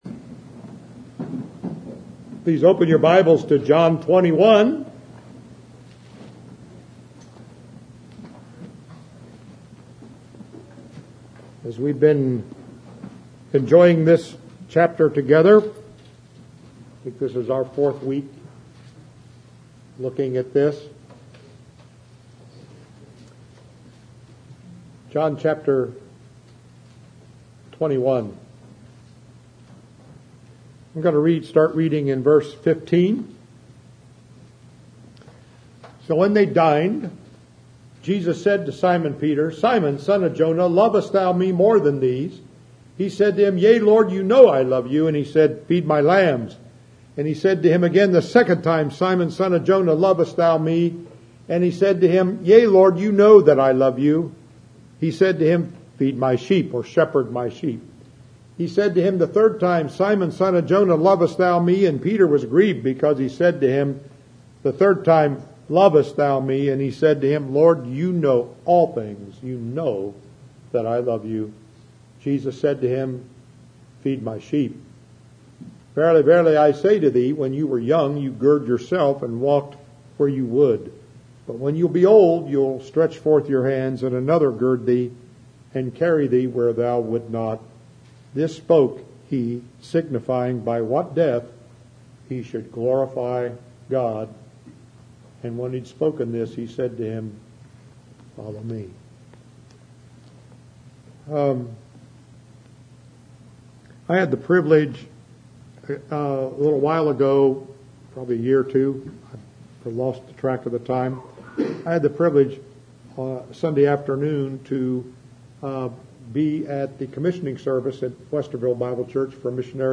John 21 15 – 19 Audio Sermon